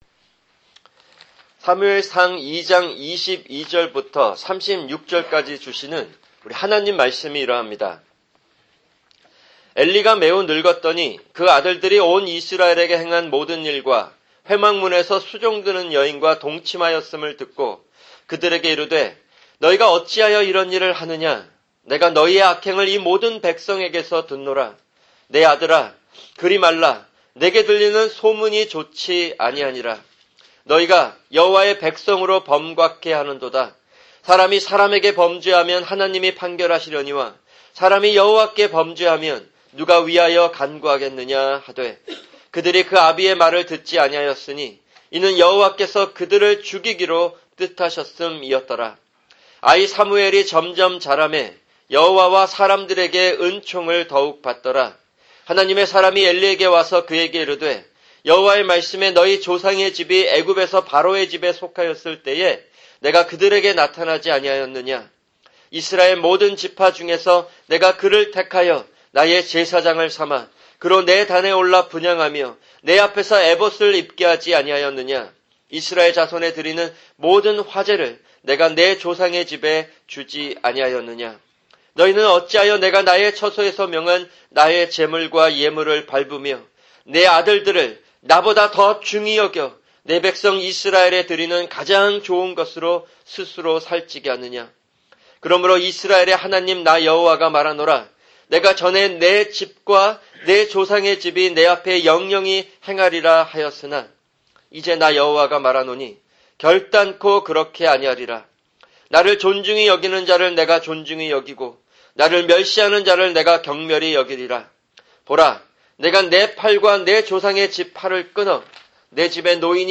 [주일 설교] 사무엘상(9) 2:22-36(2)